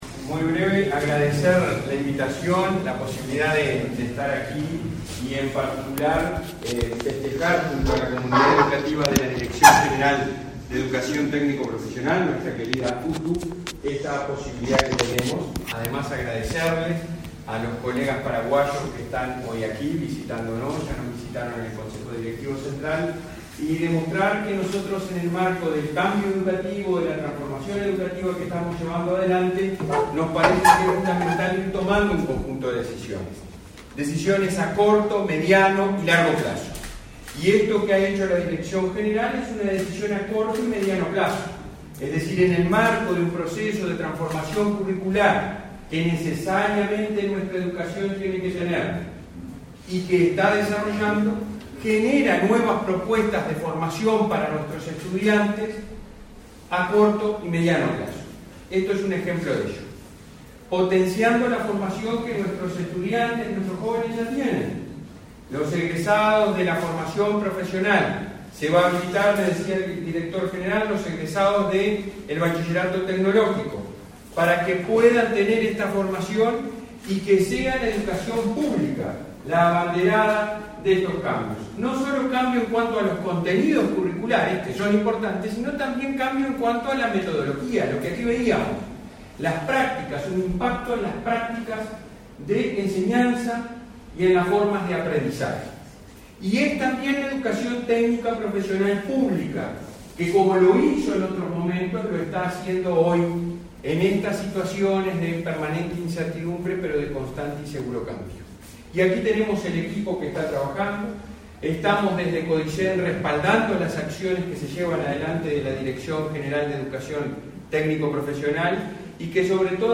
Palabras del presidente del Consejo Directivo Central de la ANEP, Robert Silva
Palabras del presidente del Consejo Directivo Central de la ANEP, Robert Silva 02/08/2022 Compartir Facebook X Copiar enlace WhatsApp LinkedIn La Dirección General de Educación Técnico Profesional-UTU de la Administración Nacional de Educación Pública (ANEP), presentó, este 2 de agosto, el Bachillerato en Movilidad Eléctrica. Participó del evento, el presidente del Codicen.